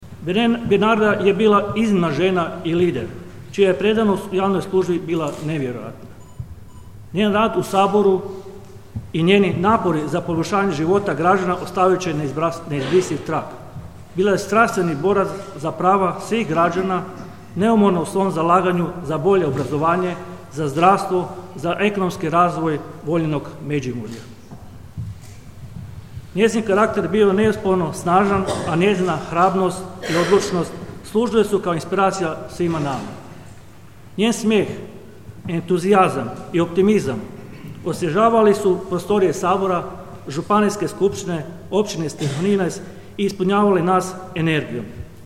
Skupština Međimurske županije održala je komemorativnu sjednicu u sjećanje na aktualnu županijsku vijećnicu i bivšu saborsku zastupnicu Bernardu Topolko (65).